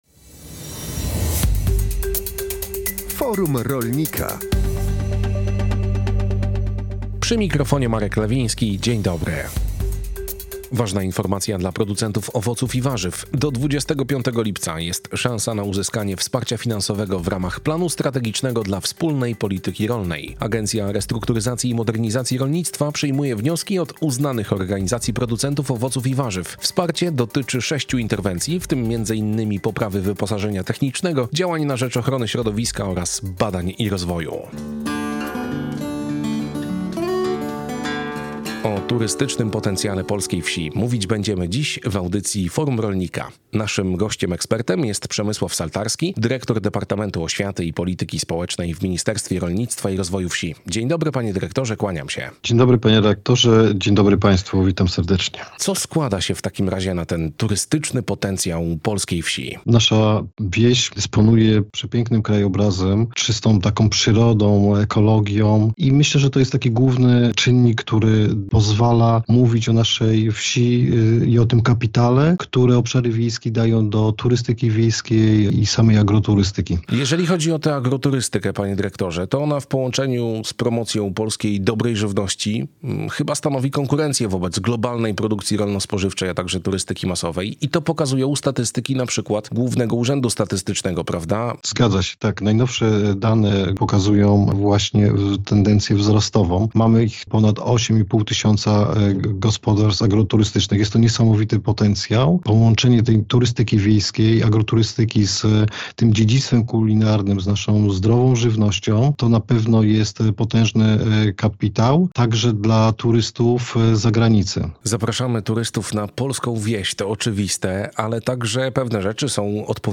Audycja o tematyce rolnej „Forum Rolnika” emitowana jest na antenie Radia Kielce w środy po godz. 12.